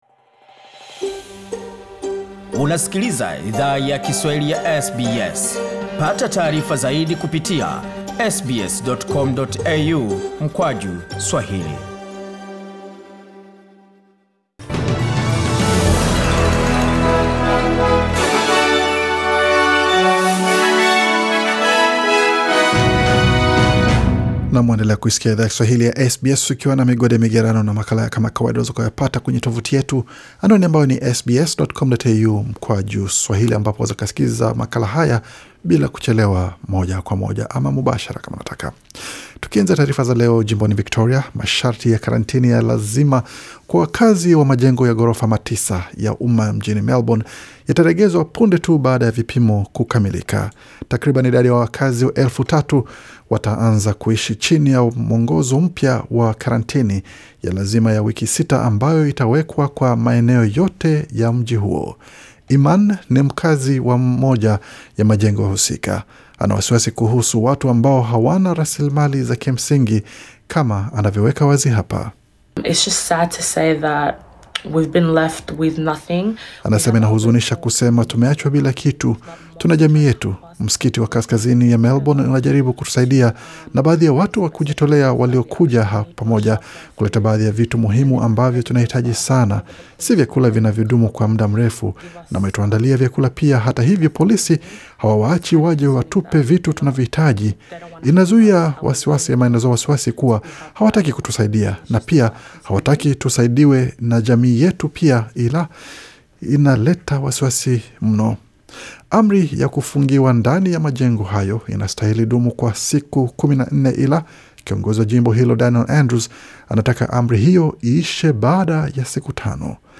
Taarifa ya habari 7 Julai 2020